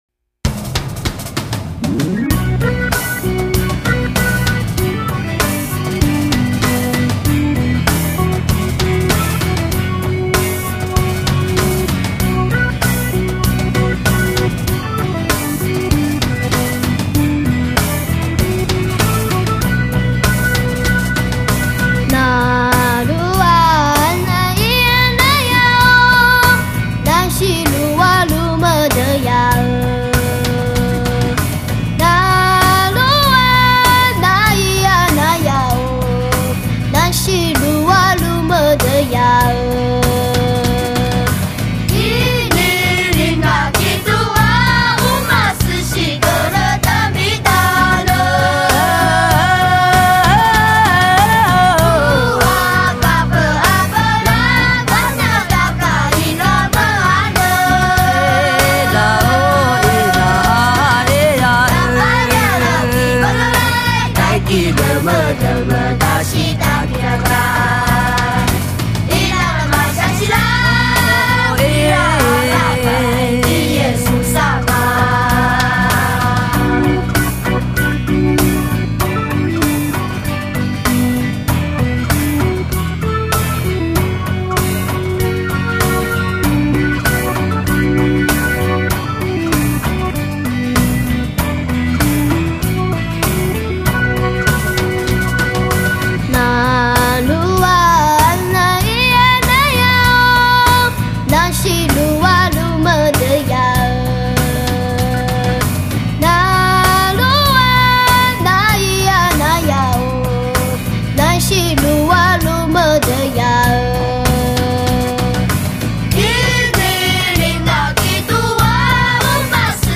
原住民音乐
录音室：高雄亚洲数位录音室
更自然、更原味、更真实的唱法！
原住民儿童的天真与欢乐
Displays the purity which the indigenous people child sings.
用他们的天真、欢乐的歌声！